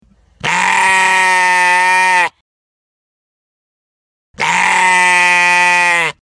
Звуки, которые издают овцы и бараны в нескольких вариантах.
12. Голос барана «БЭЭЭЭ» (2 раза)
baran-beeeee.mp3